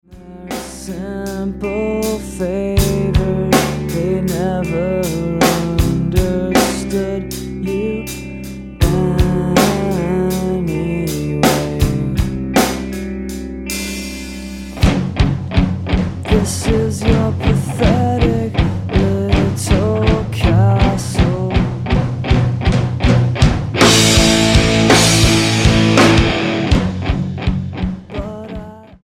STYLE: Rock
abrasive, post punk, emo rock